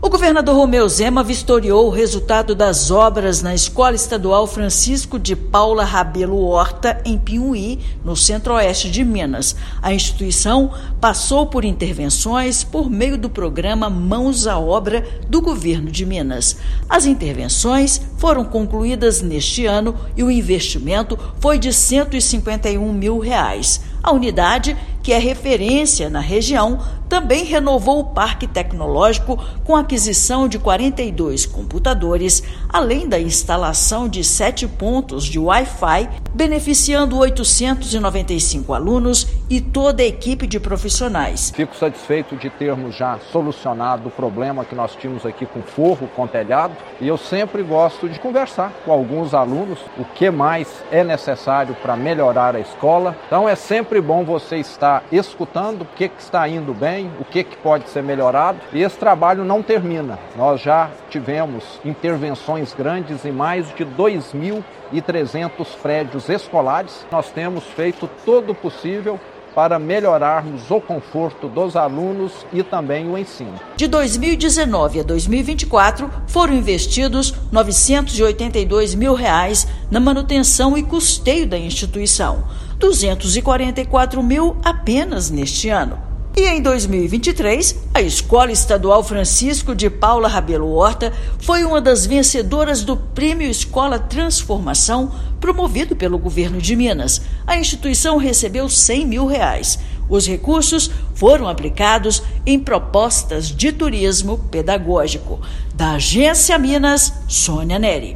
Com recursos do programa e do prêmio Escola Transformação, a Escola Estadual Professor Francisco de Paula Rebelo Horta investiu em viagens para estudantes, reforma de salas, melhorias em quadra e estruturação de laboratórios. Ouça matéria de rádio.